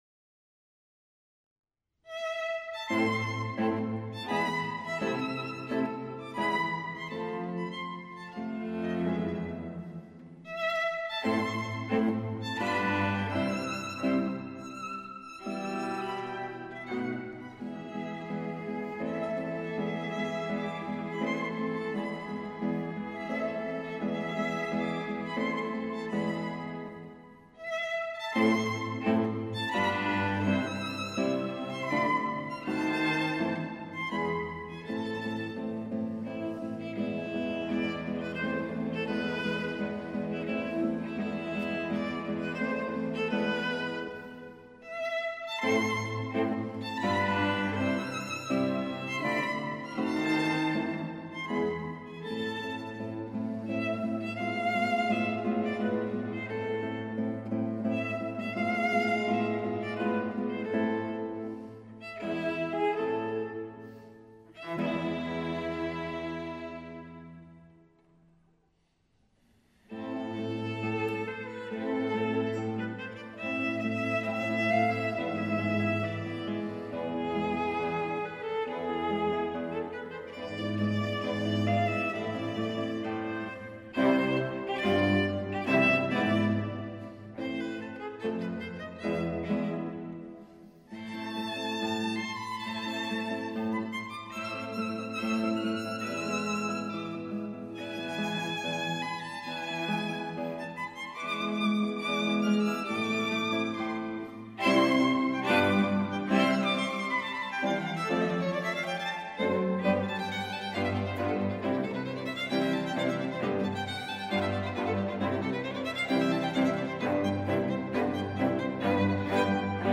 Quartetto per violino, viola,
violoncello e chitarra op.4 n.1